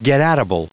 Prononciation du mot getatable en anglais (fichier audio)
Prononciation du mot : getatable